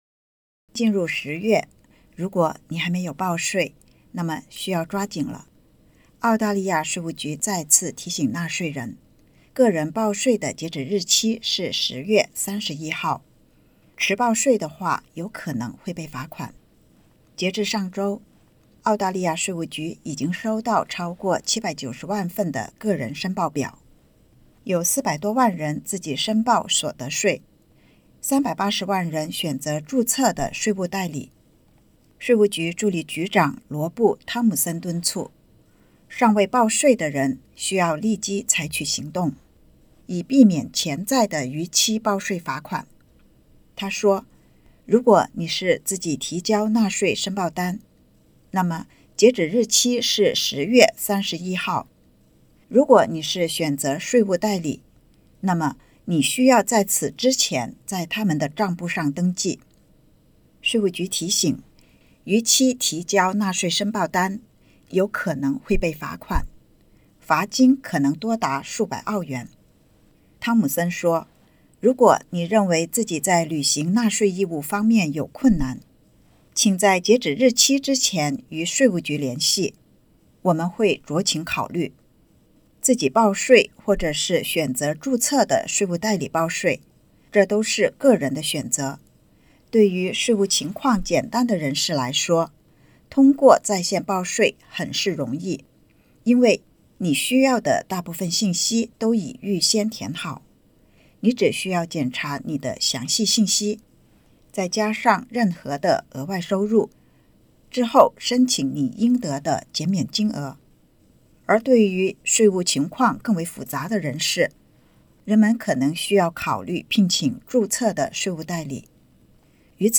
（请点击音频播放键收听报道）